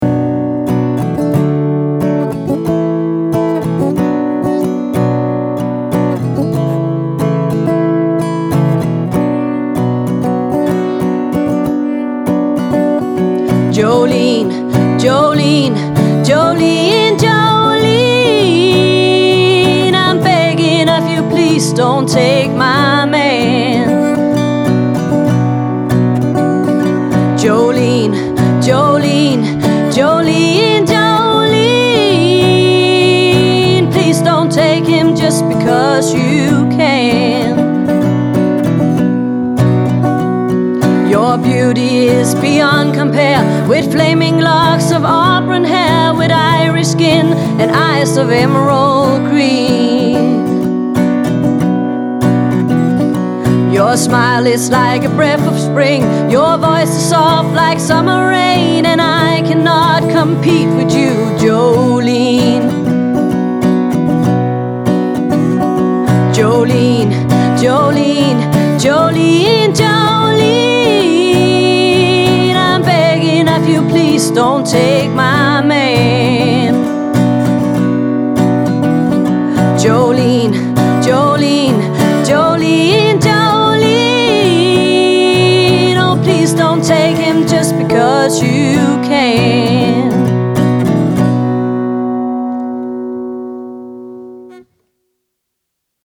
Musikken er rolig og afslappet.